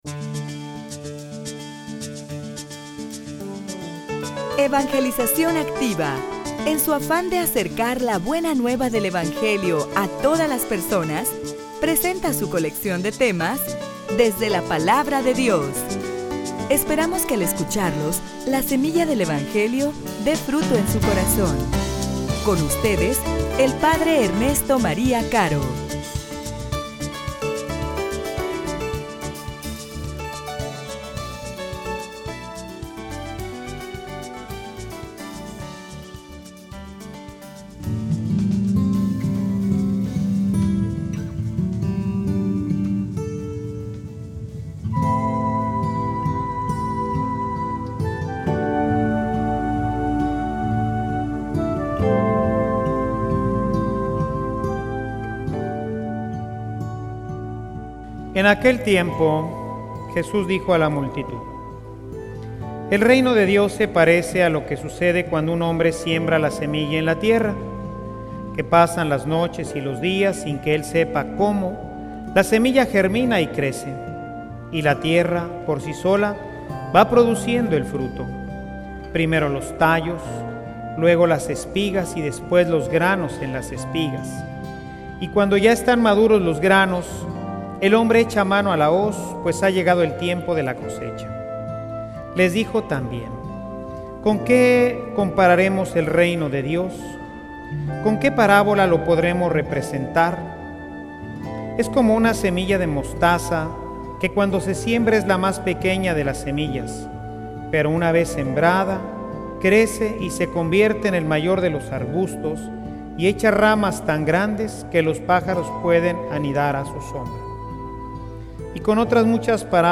homilia_Confia_Dios_esta_obrando.mp3